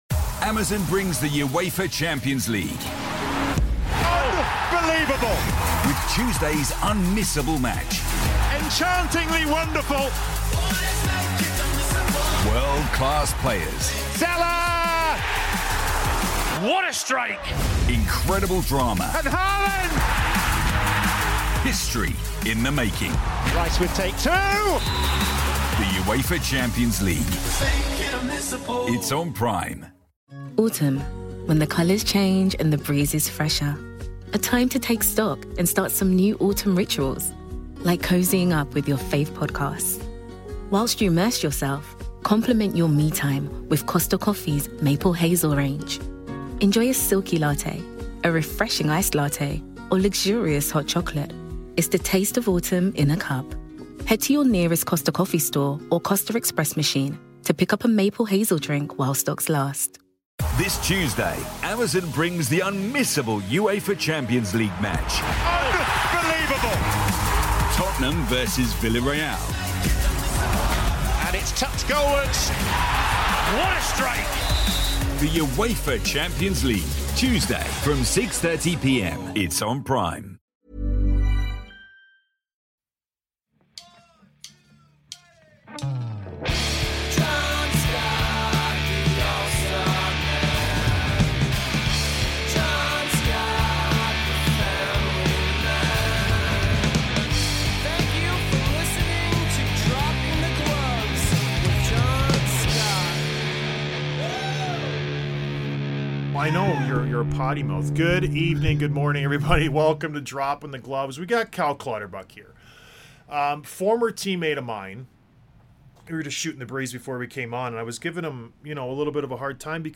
Interview w/ Cal Clutterbuck